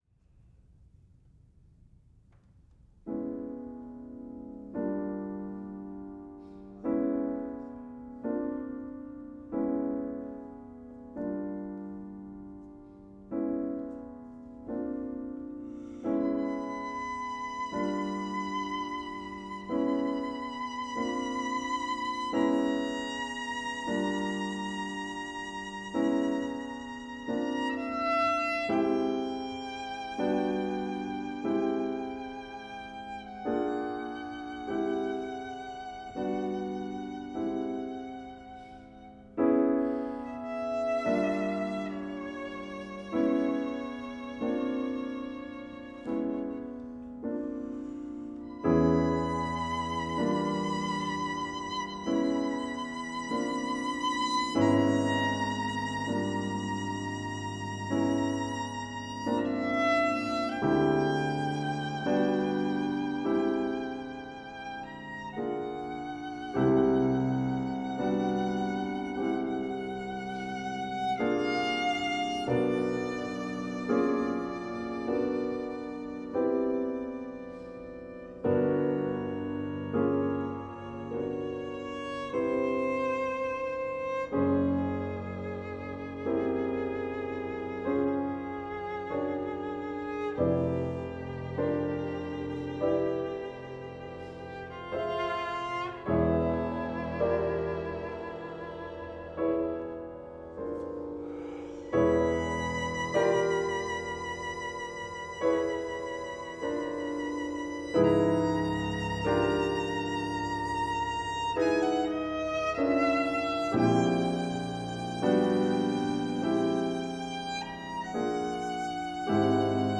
Chamber/Choral Music
The second, Hymn to the World Tree: Lament, is the third movement of a four-movement sonata for violin and piano–a dramatic piece about death and grief.